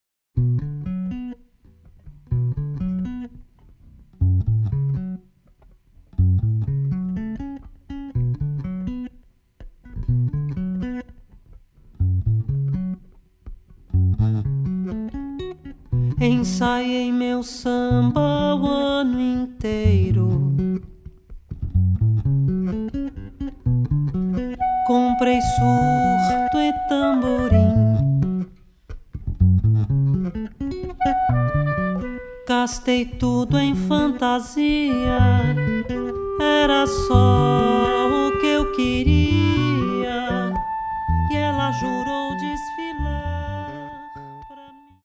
Voz
Piano
Baixo
Bateria
Clarinete